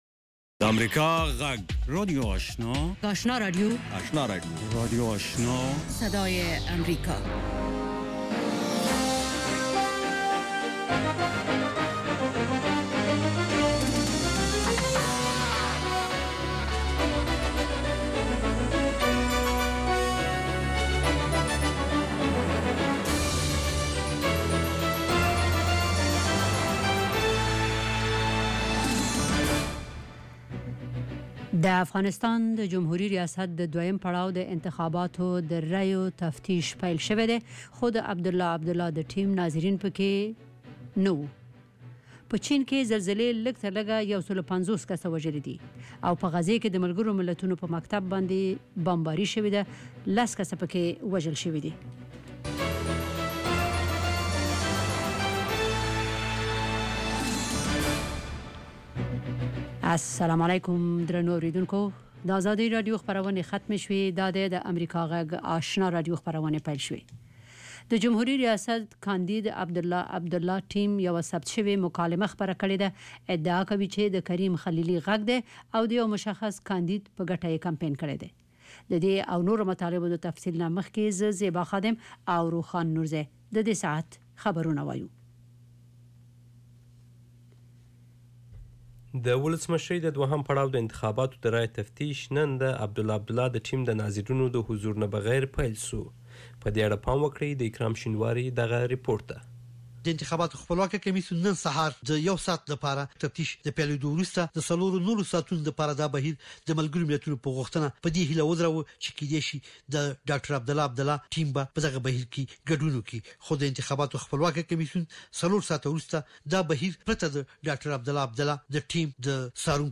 یو ساعته پروگرام: د ورځې د مهمو سیاسي، اجتماعي او نورو مسایلو په اړه له افغان چارواکو او کارپوهانو سره خبرې کیږي. د اوریدونکو پوښتنو ته ځوابونه ویل کیږي. ددغه پروگرام په لومړیو ١٠ دقیقو کې د افغانستان او نړۍ وروستي خبرونه اورئ.